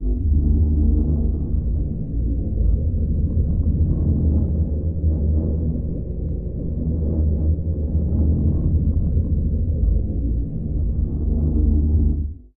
Sci-Fi Ambiences
AFX_ELCTRO_ENERGYFIELD_2_D.WAV